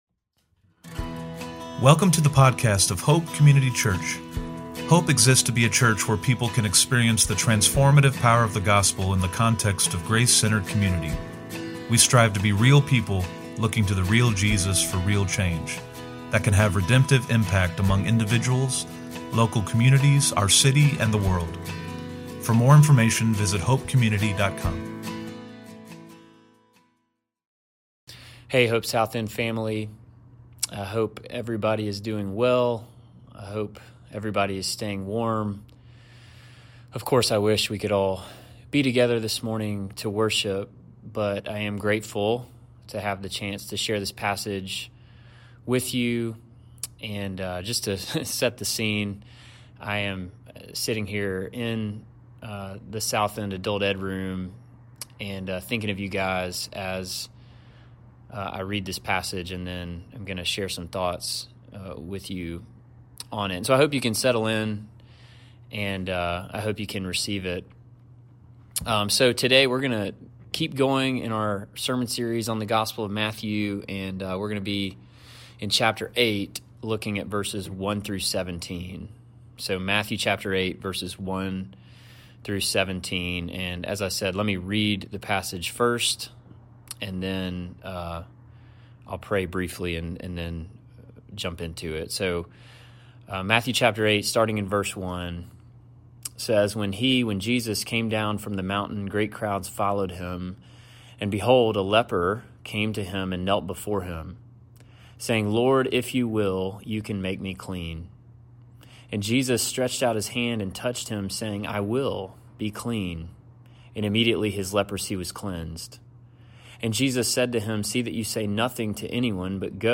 SE-Sermon-2.1.26.mp3